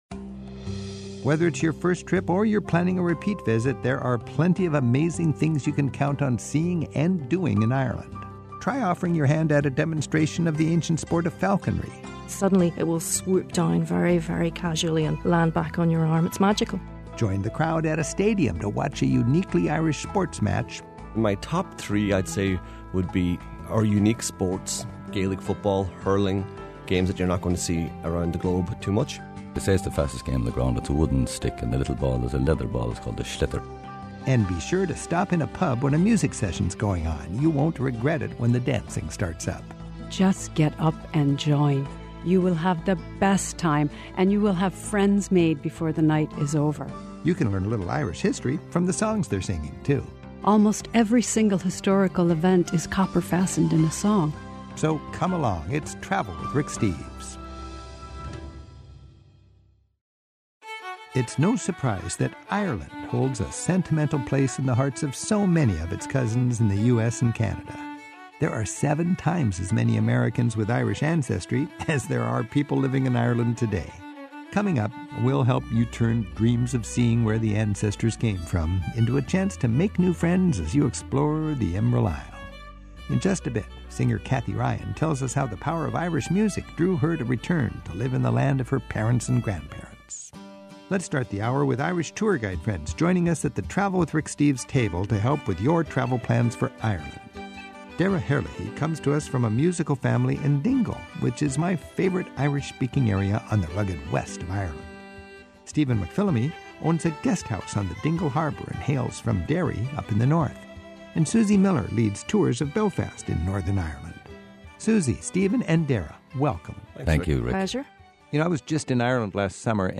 My Sentiment & Notes 825 First Timer's Ireland; At Home in Ireland Podcast: Travel with Rick Steves Published On: Sat Mar 14 2026 Description: Tour guides from the north and south of Ireland let us in on their picks for sights and experiences that will make a first trip to the Emerald Isle a memorable one. Plus, an Irish American singer explains the importance of music to the Irish and how the island's history is found in its folk ballads and songs.